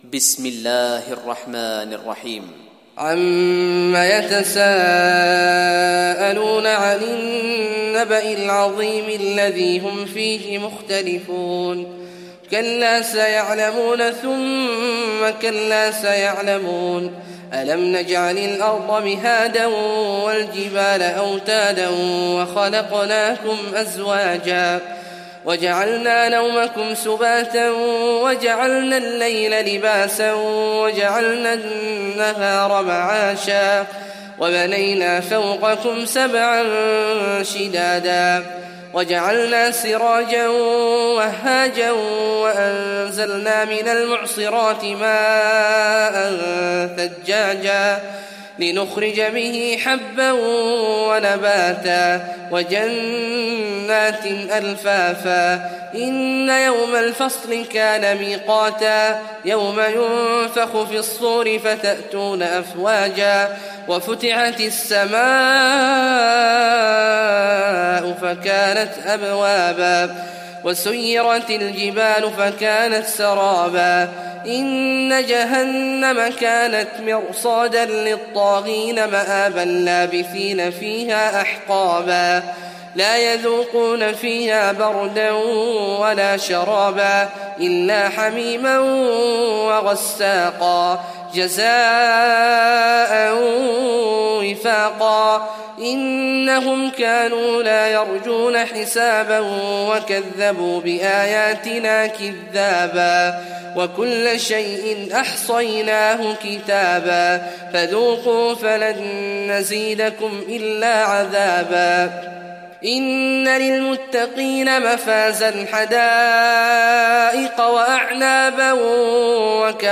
تراويح ليلة 29 رمضان 1419هـ من سورة النبأ الى الطارق Taraweeh 29th night Ramadan 1419H from Surah An-Naba to At-Taariq > تراويح الحرم النبوي عام 1419 🕌 > التراويح - تلاوات الحرمين